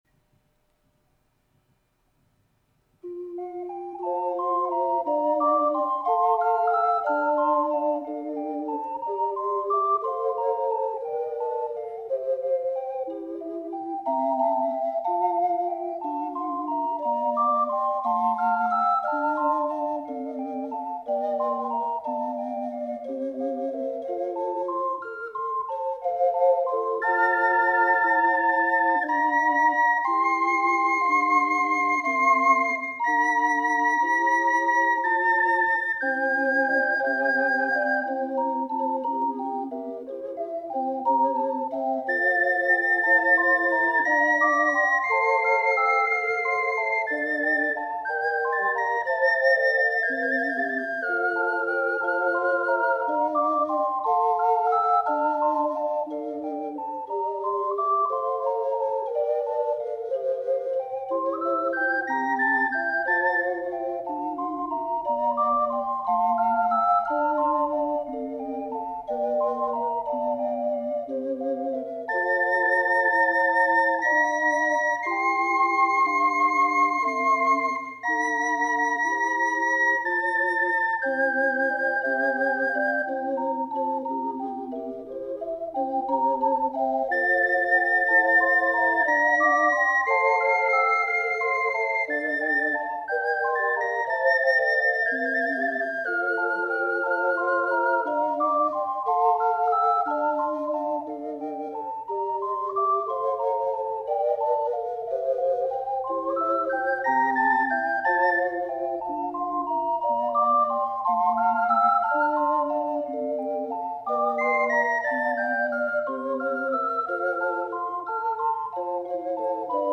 試奏は七重奏です。